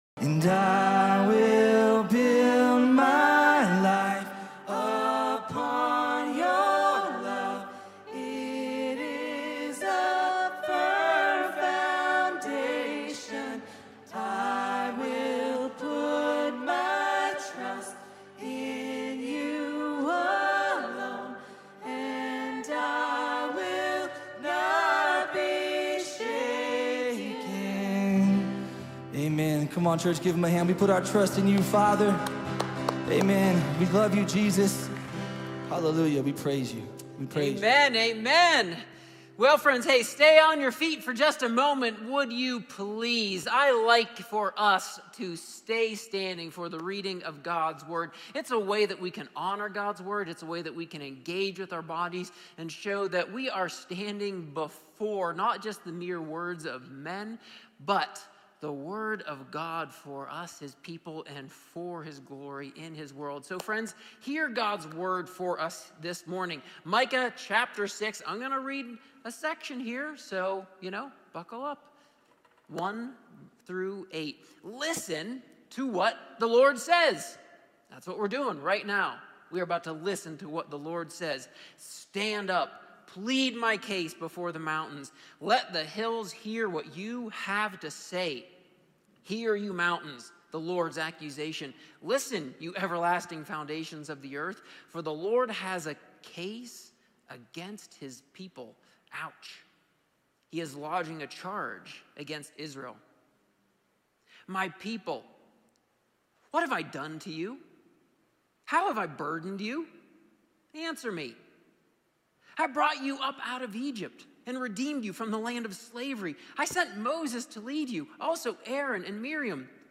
A sermon from the series "Thanksgiving 2021."